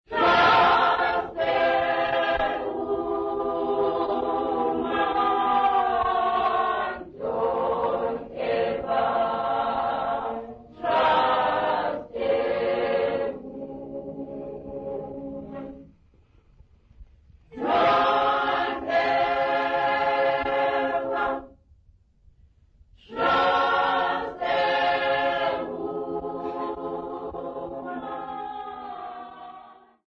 Ntsikana Memorial Church Congregation
Folk music
Sacred music
Field recordings
Africa South Africa Port Elizabeth, Eastern Cape sa
Unaccompanied church song
7.5 inch reel